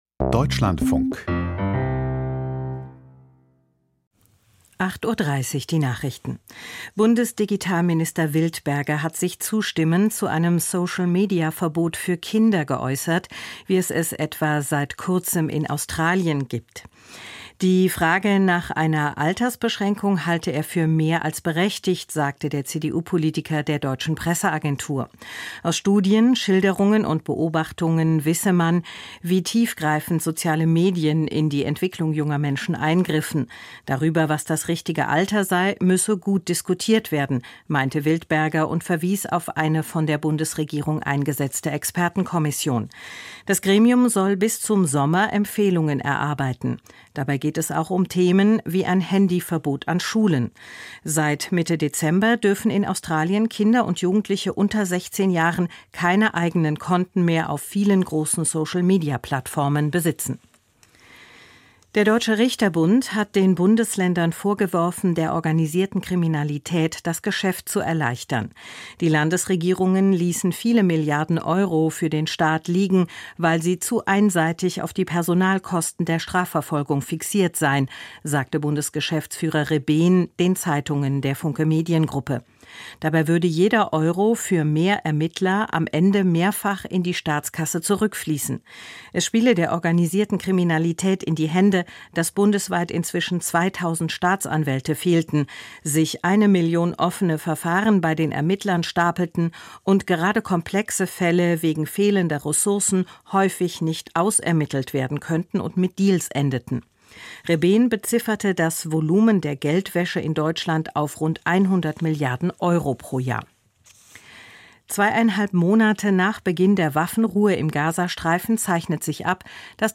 Die Nachrichten vom 26.12.2025, 08:30 Uhr
Die wichtigsten Nachrichten aus Deutschland und der Welt.